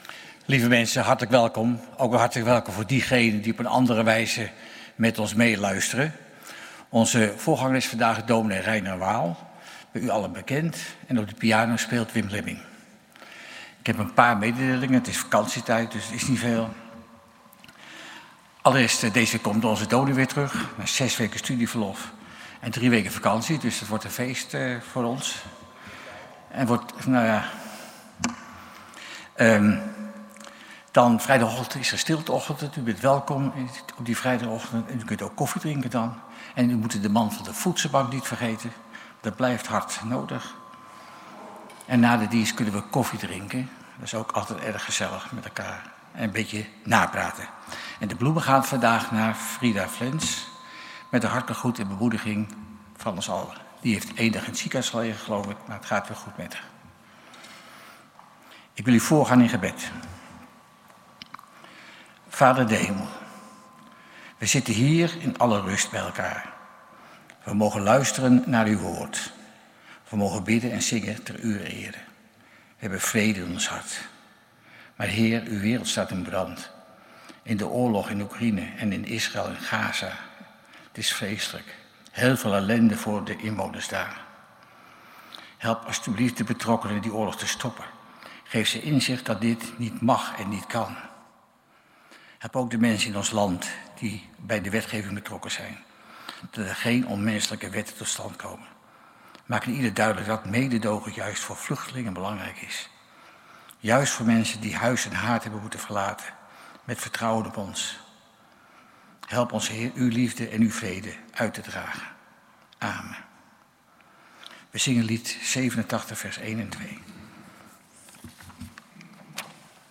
Kerkdienst geluidsopname 13 juli 2025